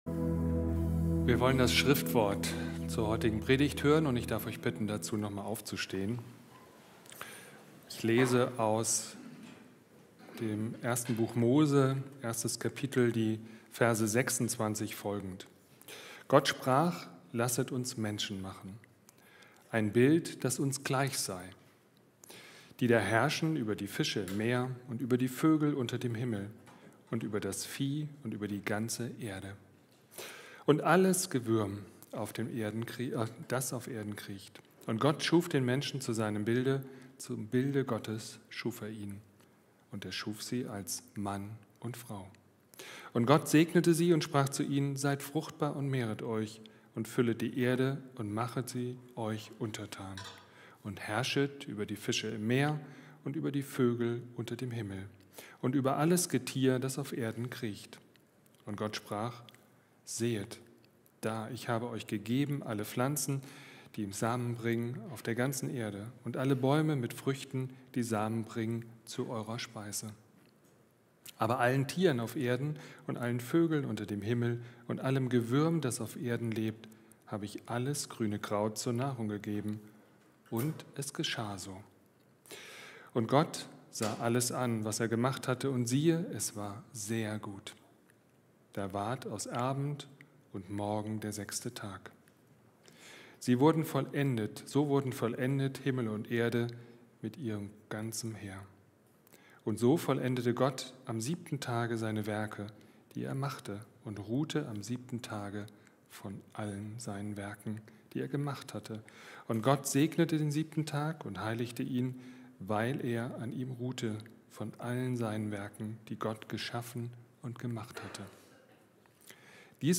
Predigt mp3